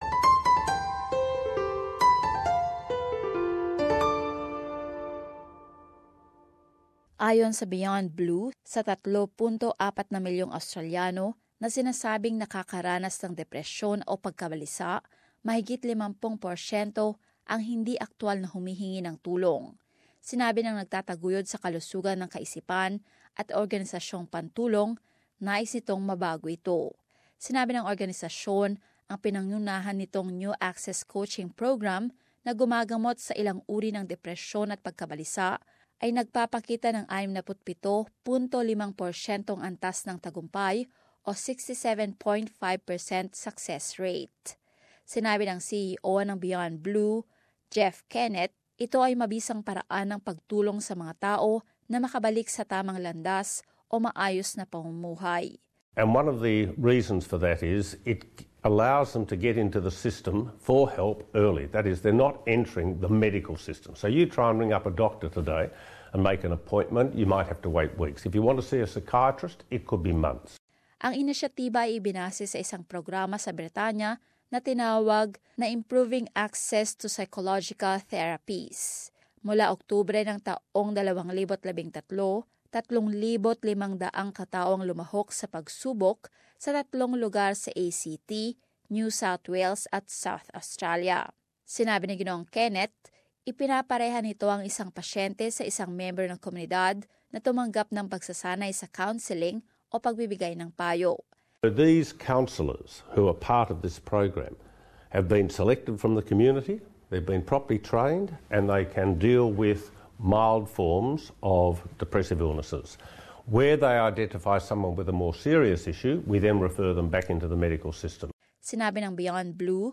As this report shows, the organisation hopes it could go national.